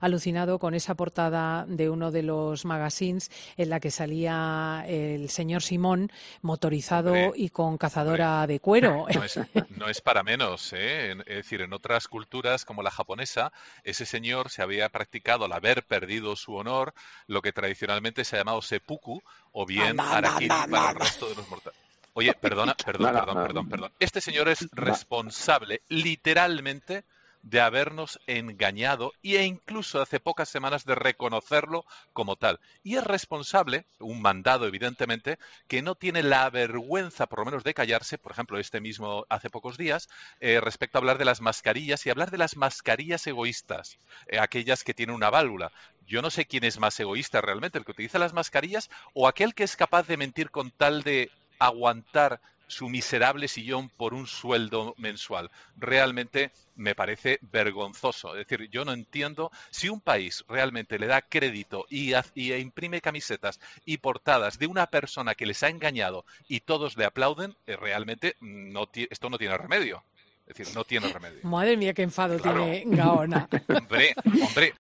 Una crítica descarnada que levantaba el asombro y las risas de todos los presentes en la tertulia por la dureza de las palabras.